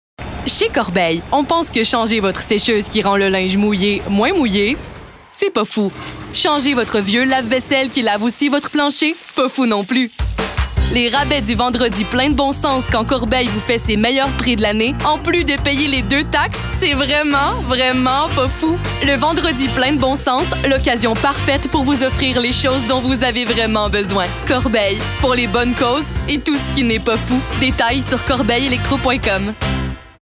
Démos voix